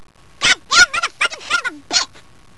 - Since when did we allow chipmunks in tha room???